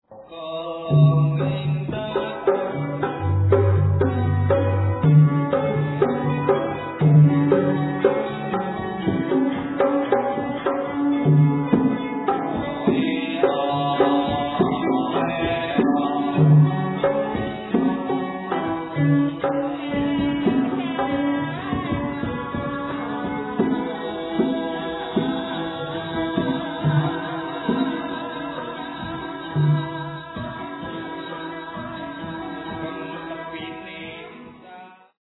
These selections were recorded in 1975 in Jogjakarta